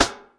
RIMSHOT  3.wav